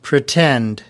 /prɪˈtend/